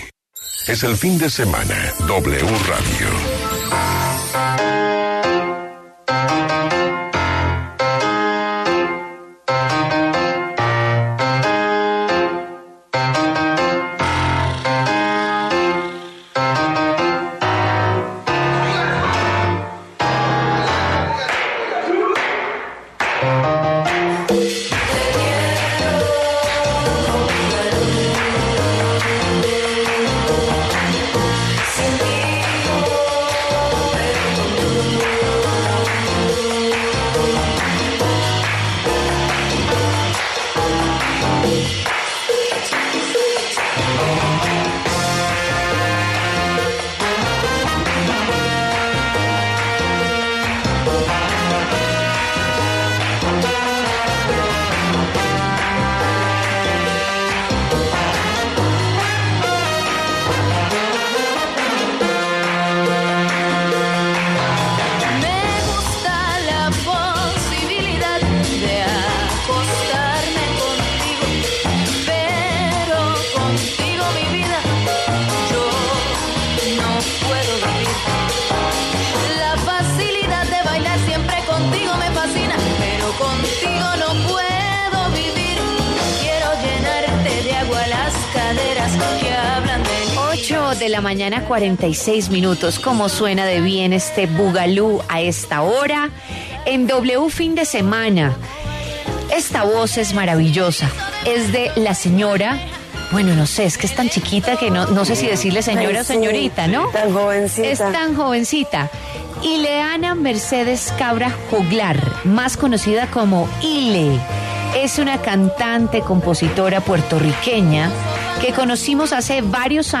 En W Fin de Semana, la cantante iLe conversó sobre su carrera musical como solista tras su paso por Calle 13 y su próximo trabajo discográfico.